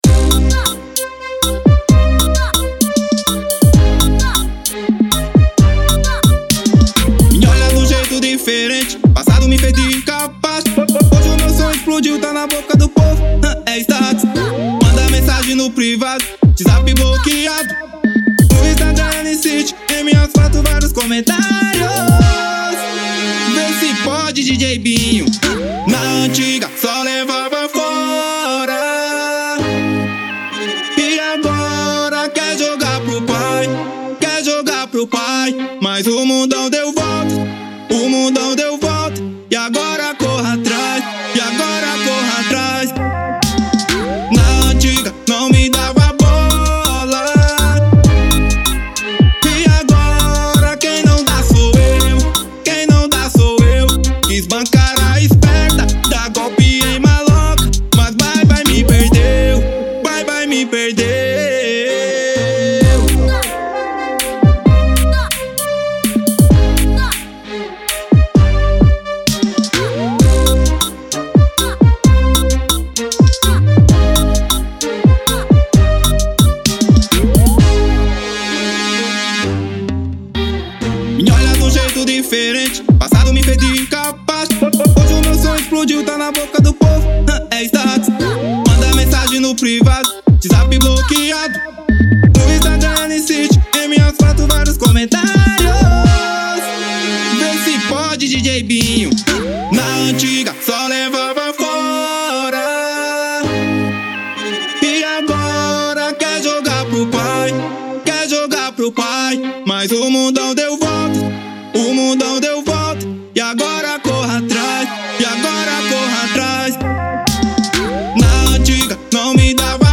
EstiloFunk